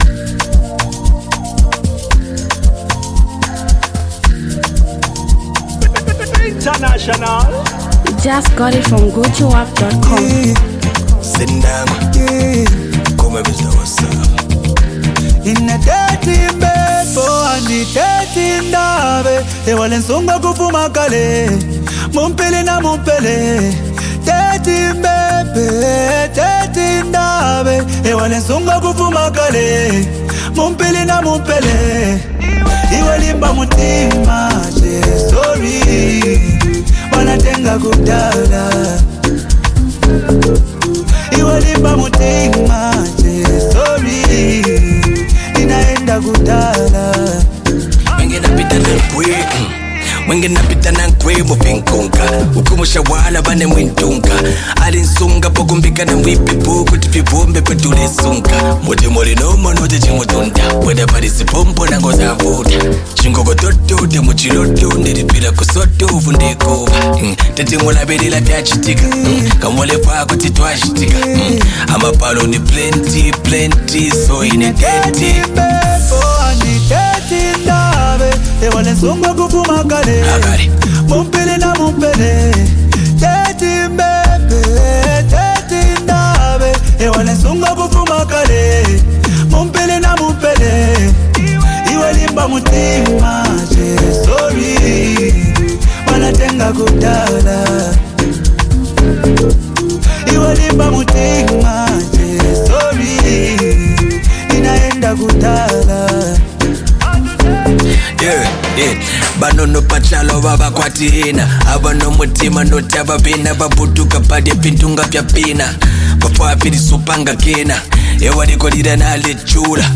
rapper, singer and songwriter.
is a heartfelt tribute love song by